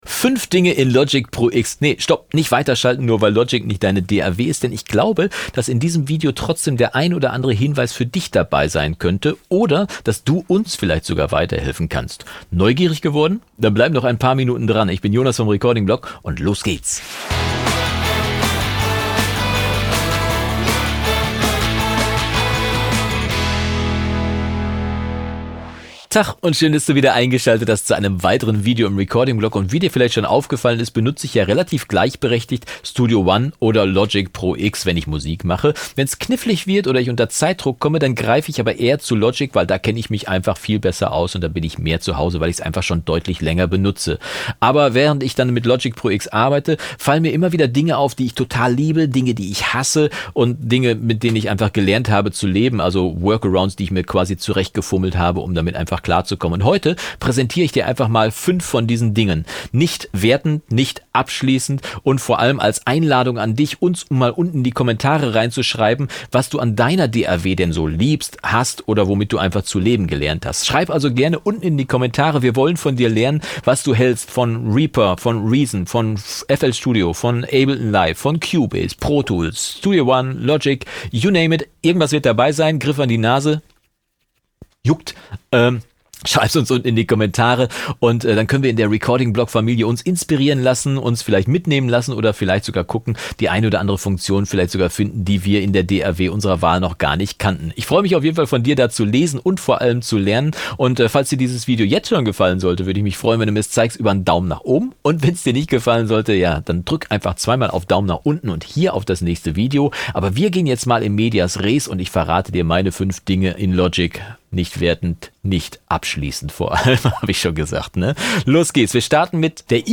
Letzte Episode Hassliebe oder nicht? 5 Dinge in Logic Pro X | Tutorial | Recording-Blog 118 16.